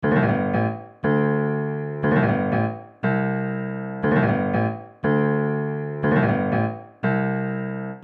描述：钢琴短语
Tag: 120 bpm Acoustic Loops Piano Loops 1.35 MB wav Key : Unknown